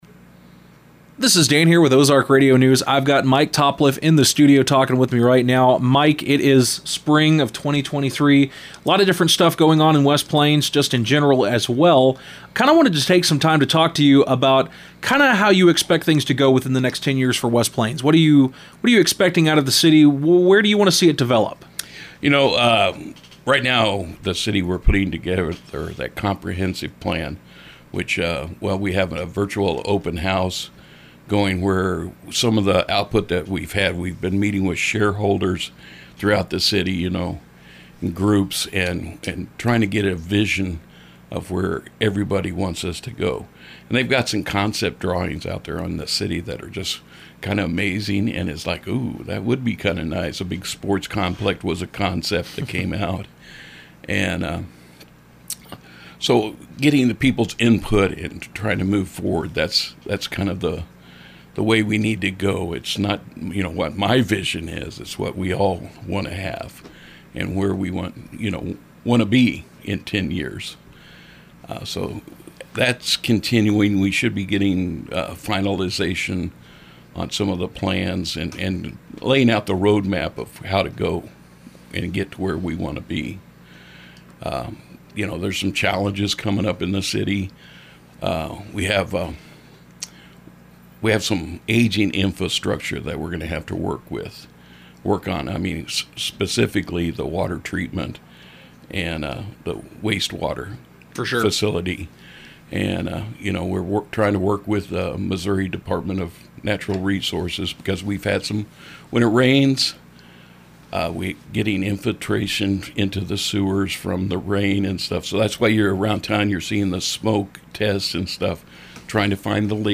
You can listen to the almost entirely unedited interview here, and listen to us look into the mind of the man behind the title, and the Hawaiian shirt.